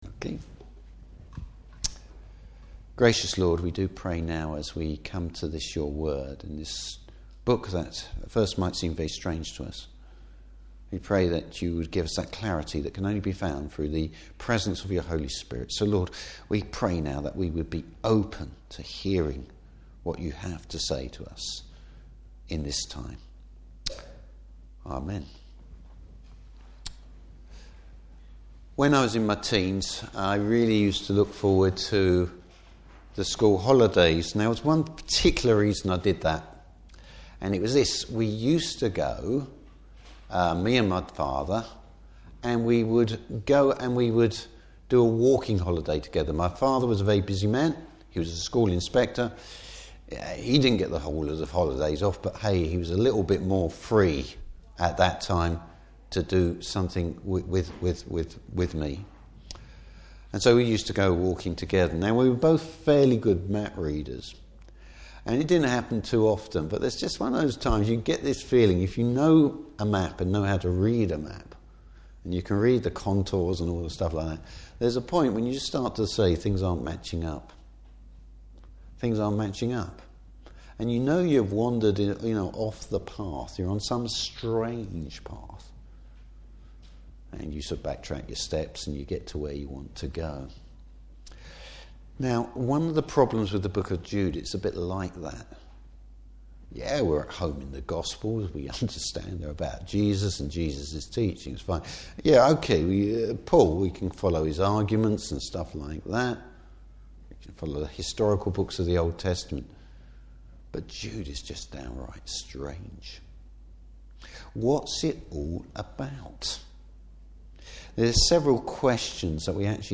Service Type: Morning Service The security the Christian has.